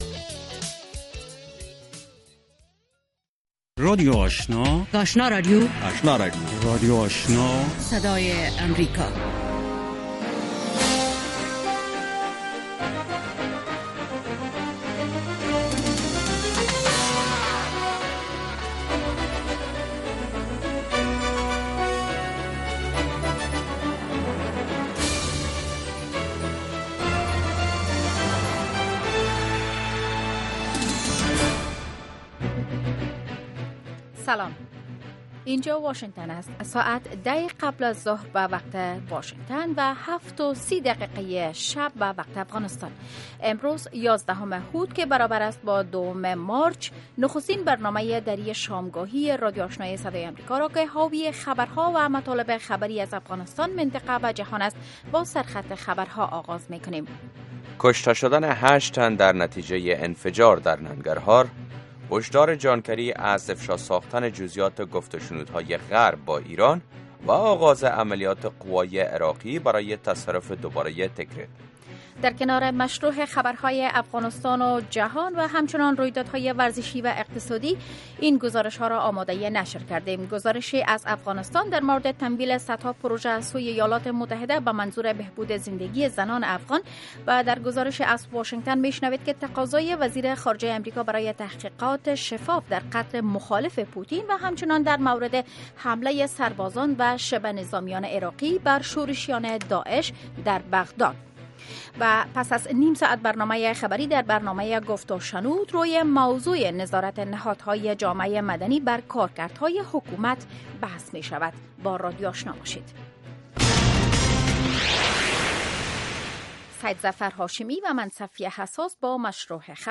در اولین برنامه خبری شب، خبرهای تازه و گزارش های دقیق از سرتاسر افغانستان، منطقه و جهان فقط در سی دقیقه.